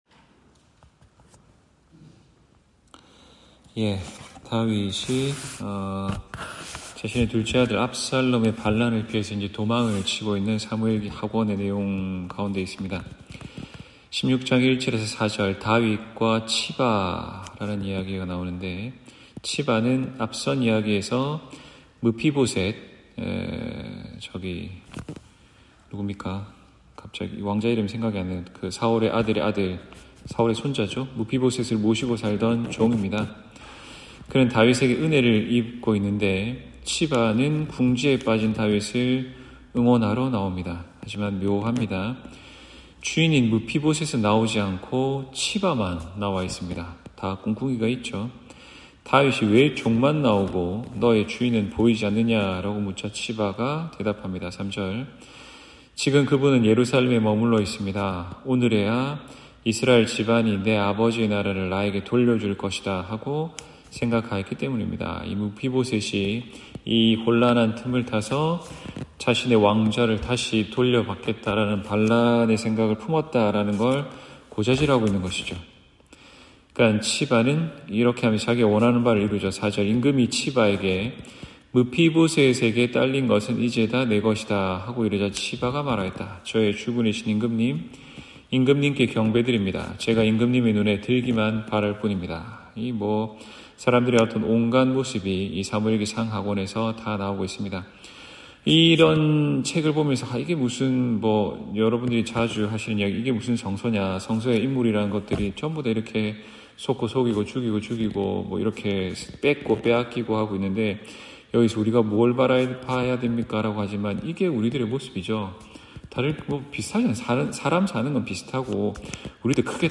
성서공부106-사무엘기하 (2024년 7월 19일 금요일)